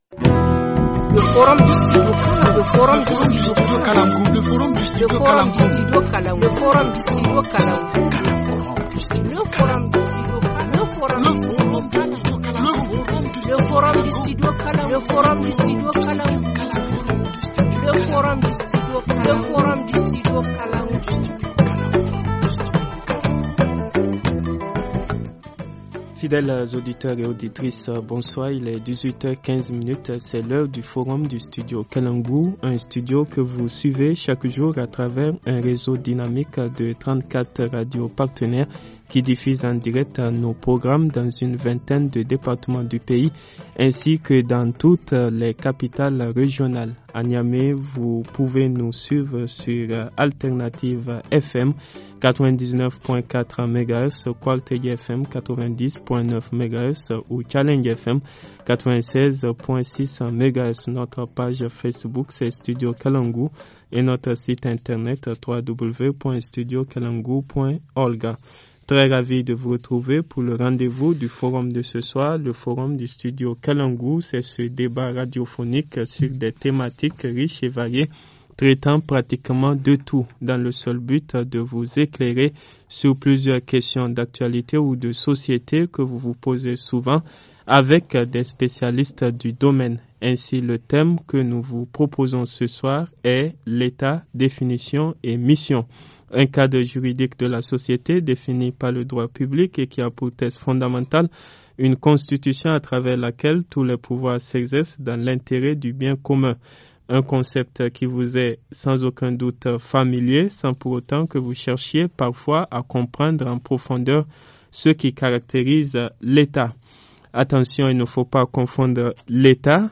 Forum en Français